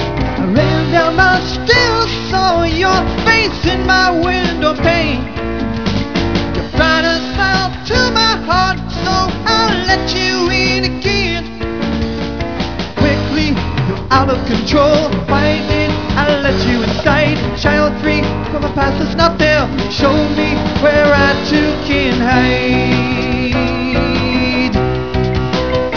NOTE: All recordings 8bit 11Khz Mono .wav files for compactness.